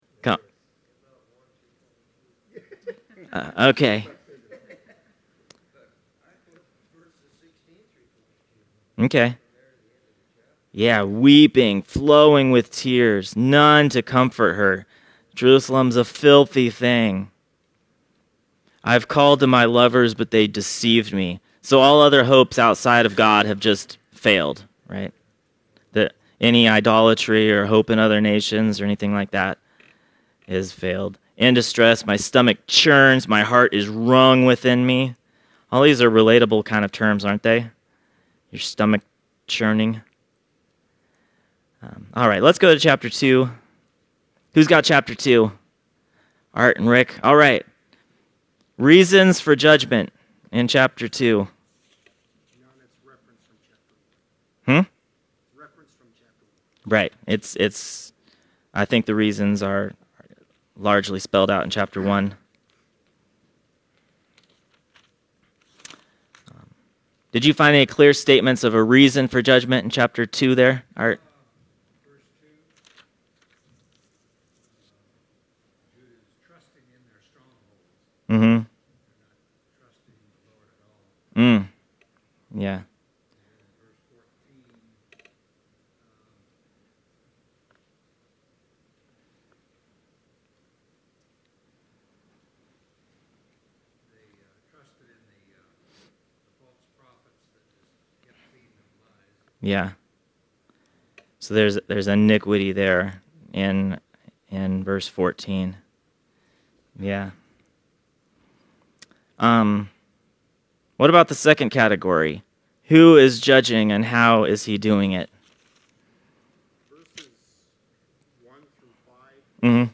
Service Type: Sunday Studies